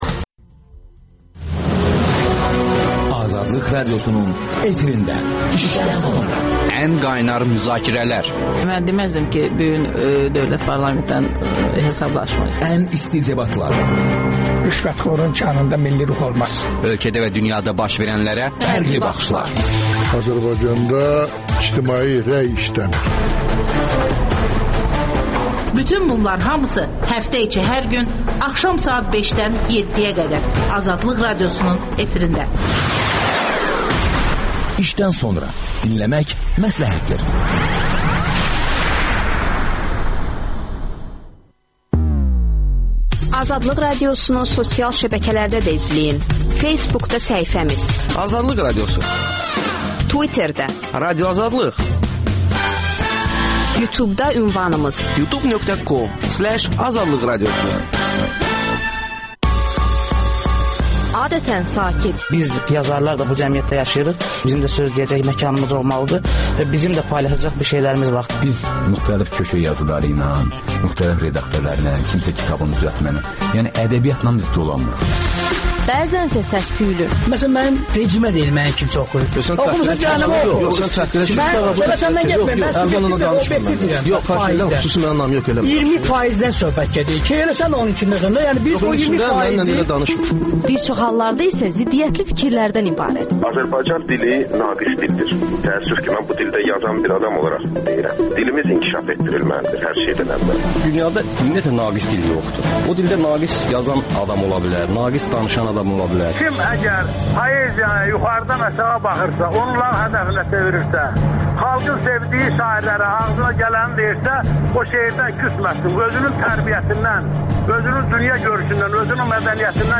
Hərbi ekspertlər